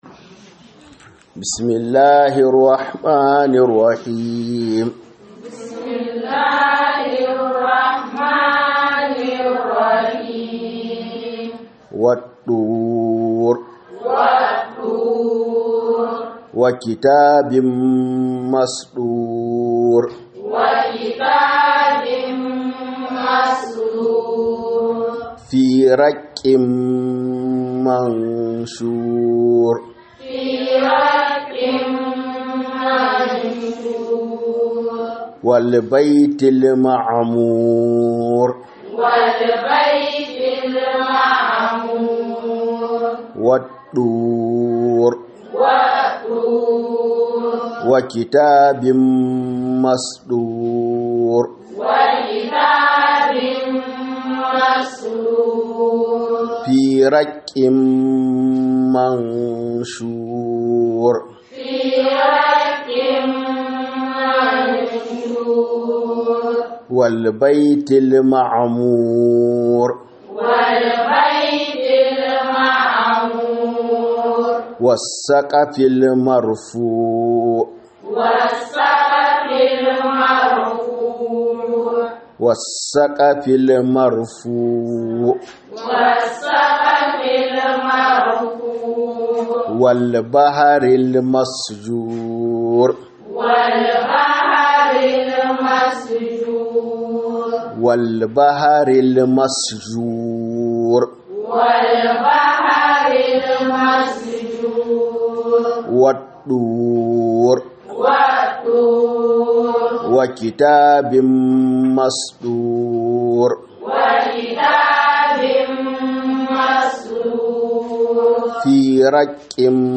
Huduba kan Shaiɗan maƙiyin ’yan Adam ne.